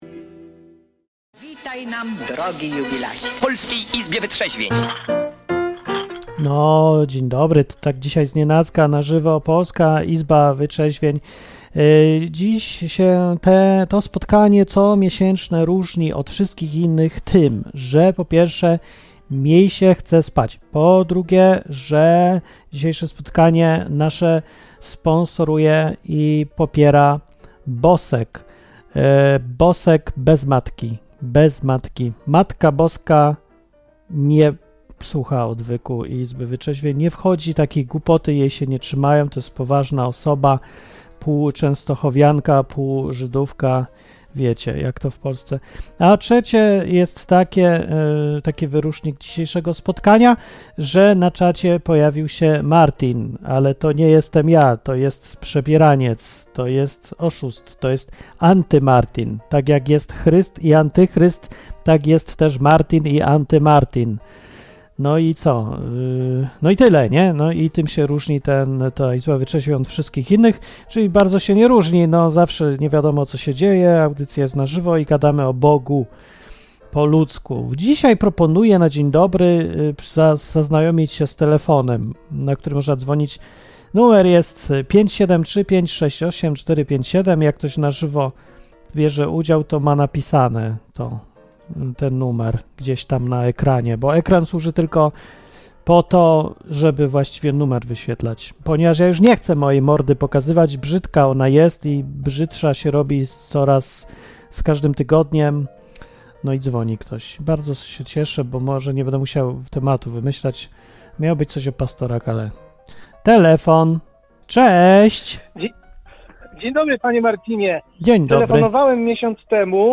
Pierwszy telefon był o tym, że spotkali się Polak, Rusek i Niemiec.